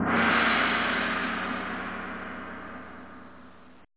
gong_wve.mp3